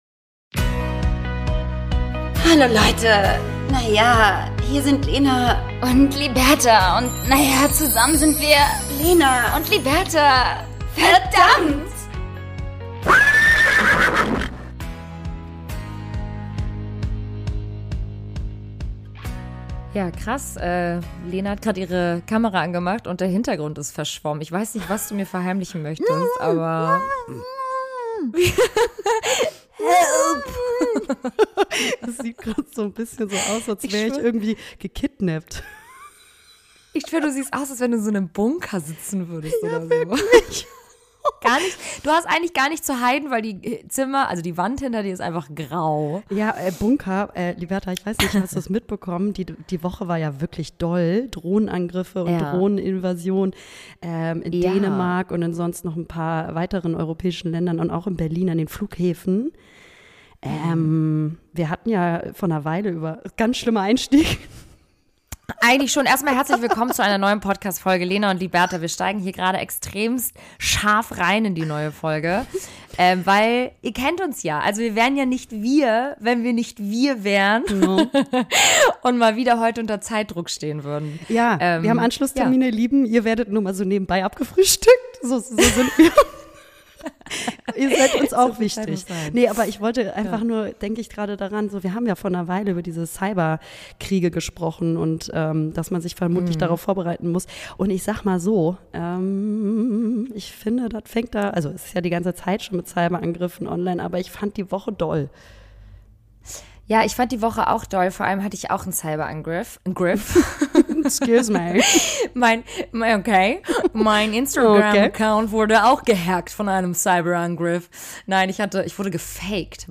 Aber trotzdem sind die Beiden wieder für euch an den Mikrofonen und berichten aus ihren letzten zwei Wochen.